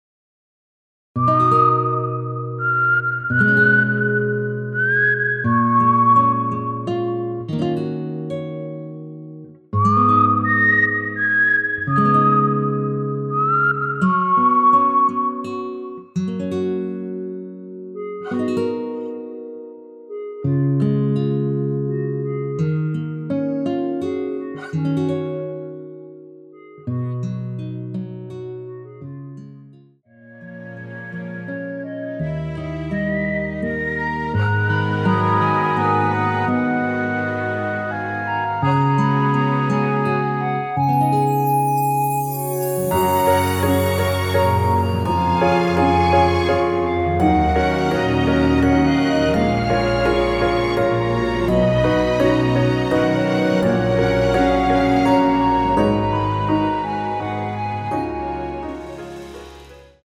무반주 구간 들어가는 부분과 박자 맞출수 있게 쉐이커로 박자 넣어 놓았습니다.(일반 MR 미리듣기 참조)
F#
◈ 곡명 옆 (-1)은 반음 내림, (+1)은 반음 올림 입니다.
앞부분30초, 뒷부분30초씩 편집해서 올려 드리고 있습니다.